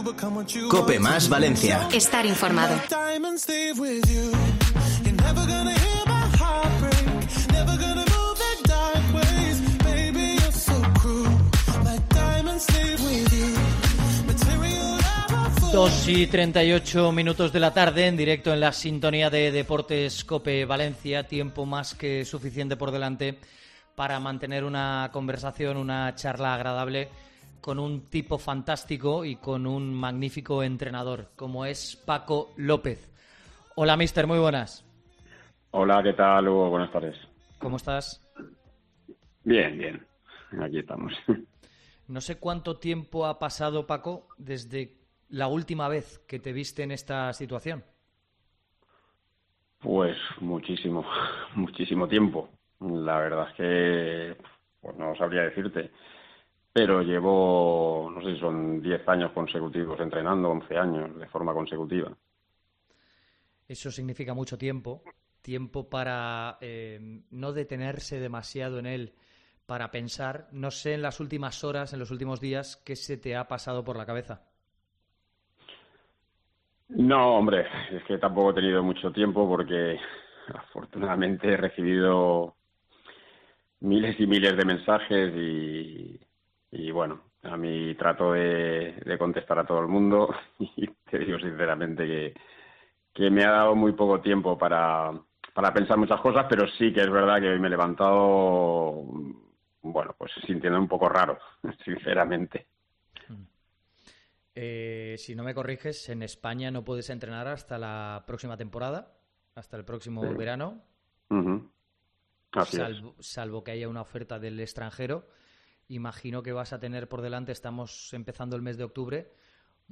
Paco López se despide en Deportes COPE Valencia en una entrevista a corazón abierto